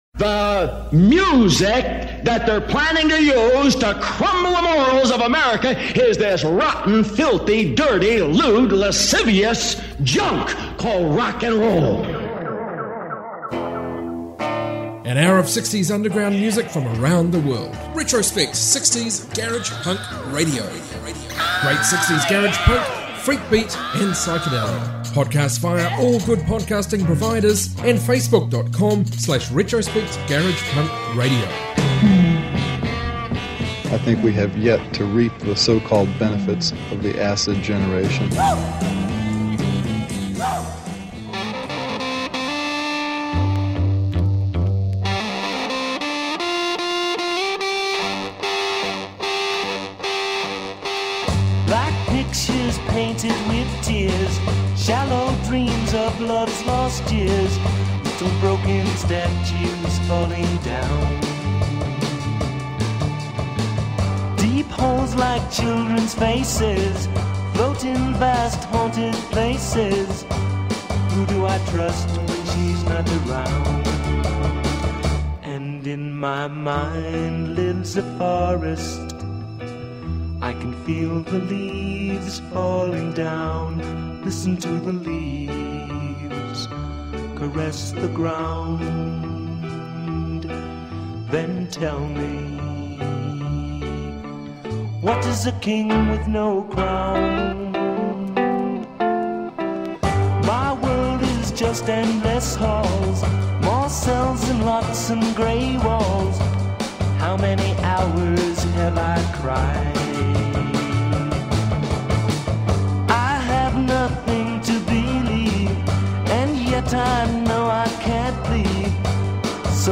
60s garage punk, garage rock, freakbeat from around the globe